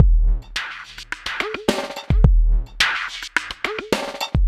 BUZZER    -R.wav